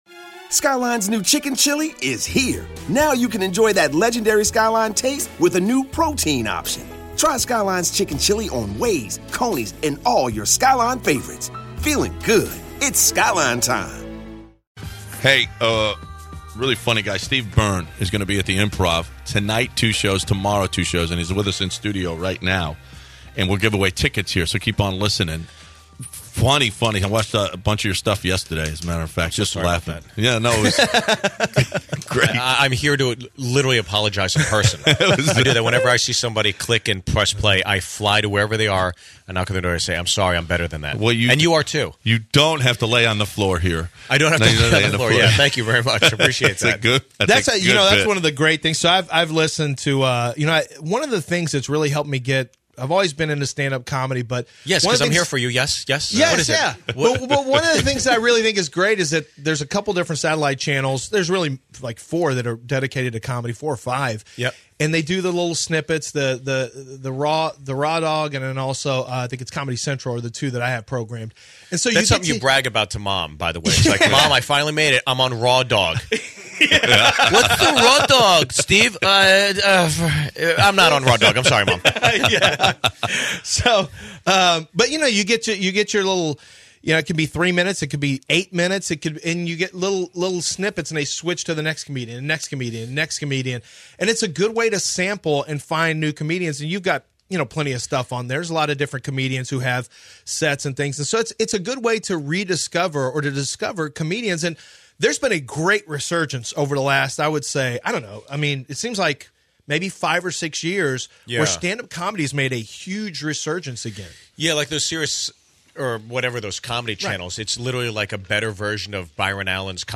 Professional comedian Steve Byrne joins the guys in the studio to discuss the profession of comedy, his process, other people in the industry, and promote his show at the Houston Improv going on tonight and tomorrow.